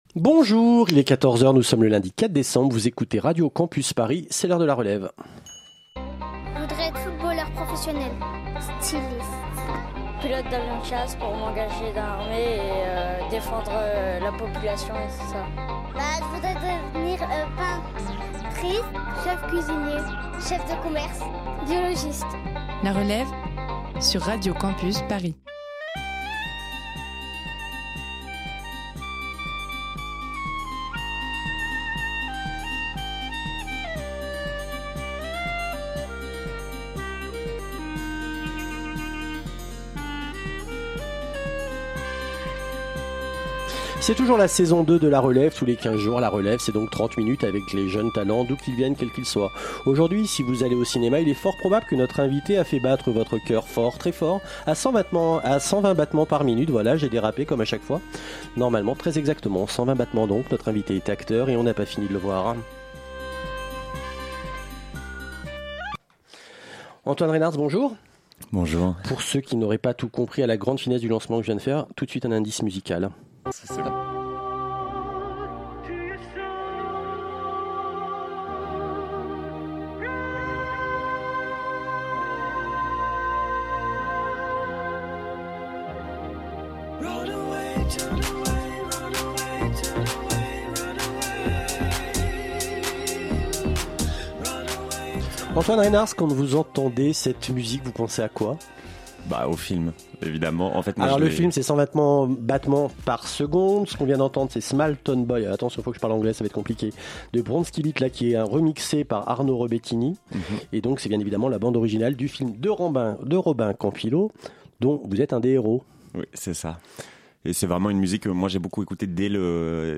Type Entretien